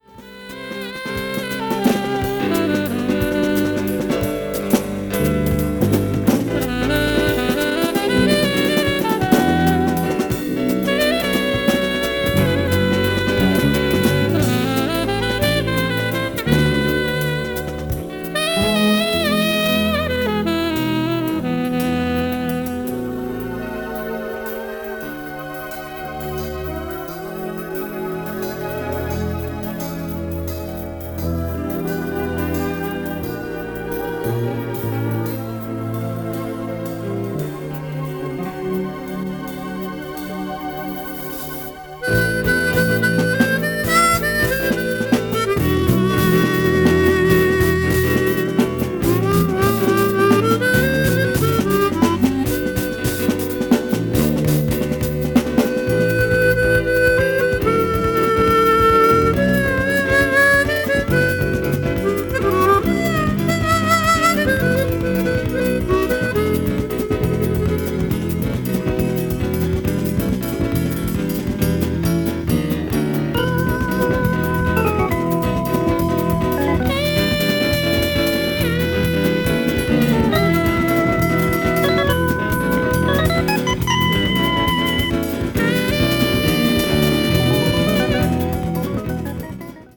contemporary jazz   crossover   fusion   mellow groove